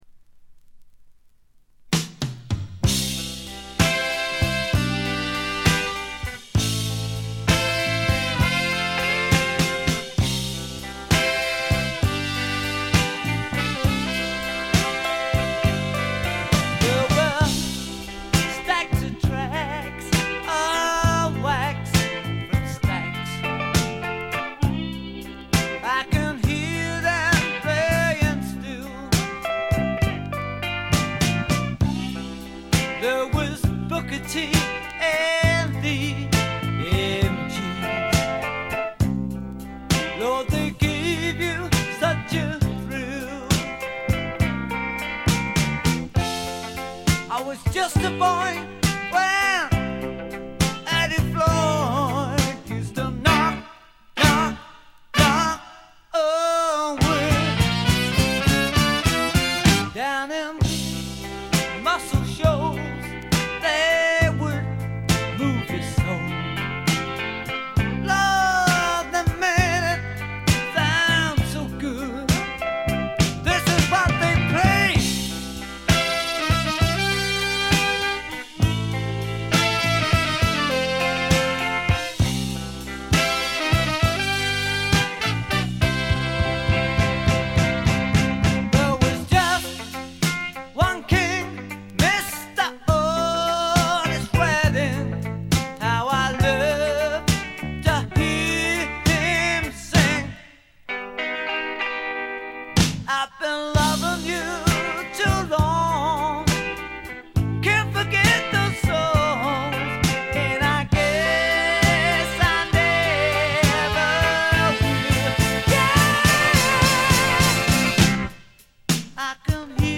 二人のホーンセクションが実によく効いてます。
試聴曲は現品からの取り込み音源です。